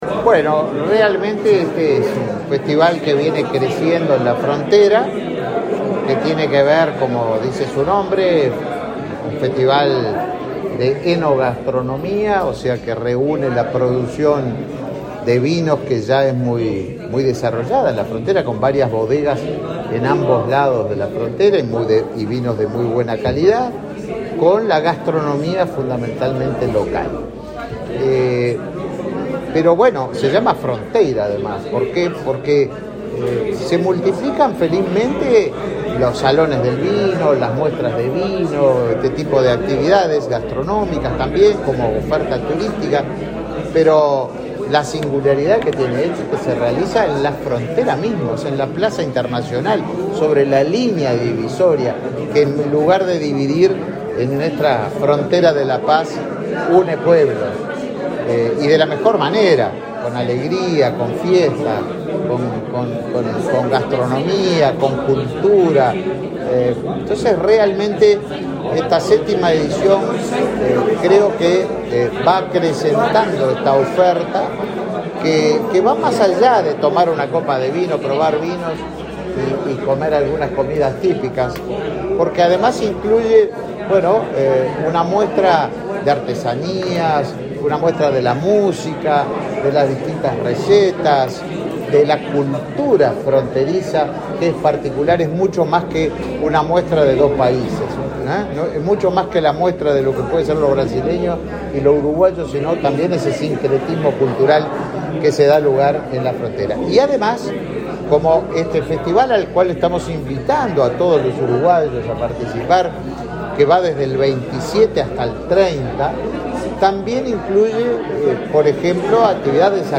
Declaraciones a la prensa del ministro de Turismo, Tabaré Viera
Declaraciones a la prensa del ministro de Turismo, Tabaré Viera 15/07/2022 Compartir Facebook X Copiar enlace WhatsApp LinkedIn El Ministerio de Turismo y la Intendencia de Rivera lanzaron el 7.° Festival Binacional de Enogastronomía, que se desarrollará entre el 27 y el 30 de julio en el Parque Internacional. El ministro Tabaré Viera dialogó con la prensa y señaló la importancia del evento.